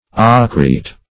Ochreate \O"chre*ate\, Ochreated \O"chre*a`ted\, a.